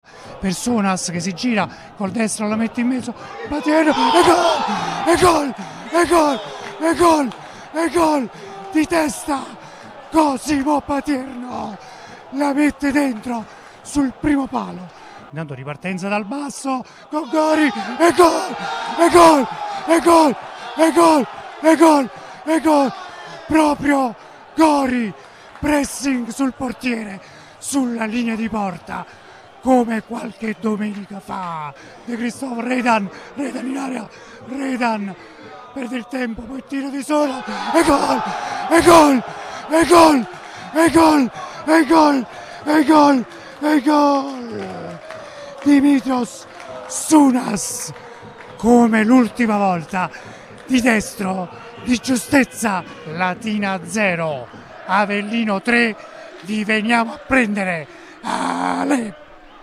I GOL DI LATINA-AVELLINO 0-3: RIASCOLTA L’ESULTANZA